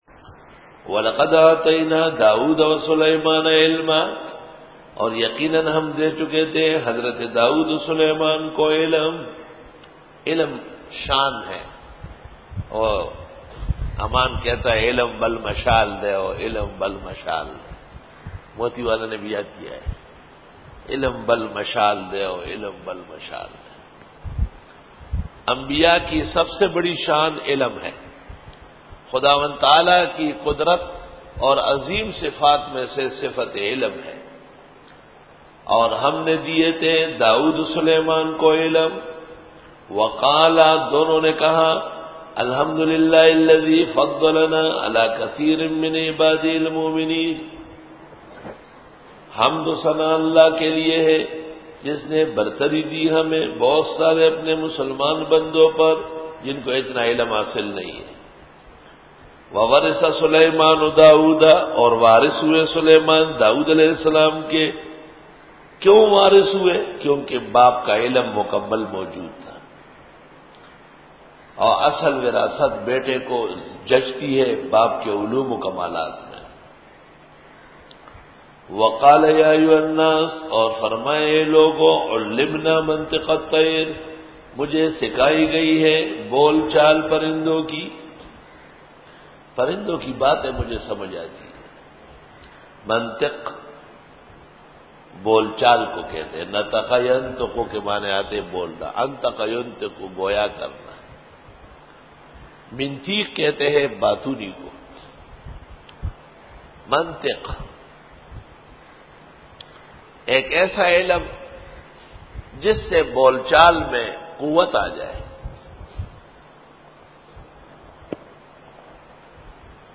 Dora-e-Tafseer 2008